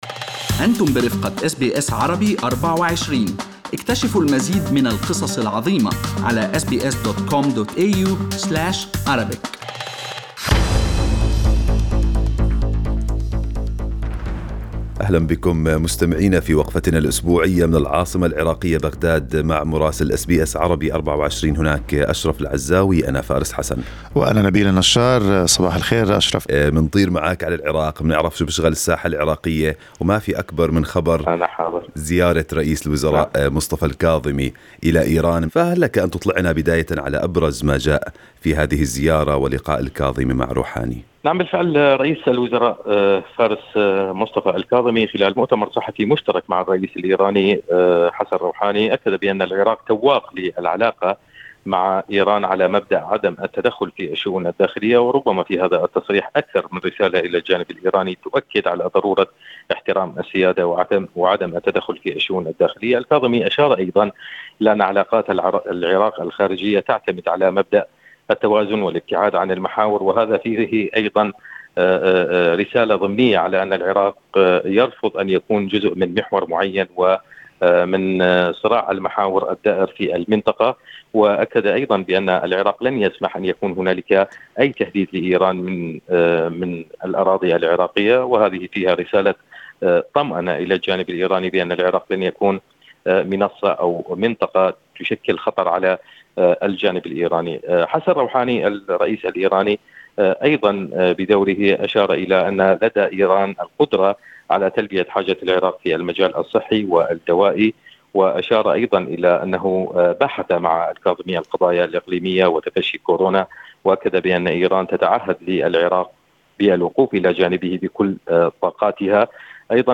من مراسلينا: أخبار العراق في أسبوع 24/07/2020